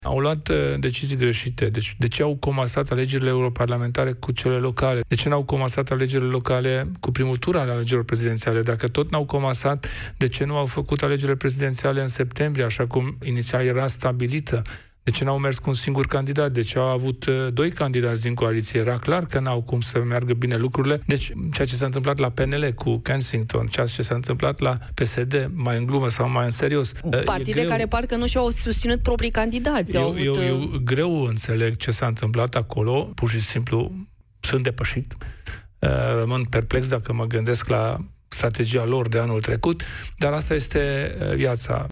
El face referire, într-un interviu în cadrul emisiunii „Piața Victoriei”, la Europa FM – la faptul că atât PSD, cât și PNL ar fi direcționat voturi către Călin Georgescu.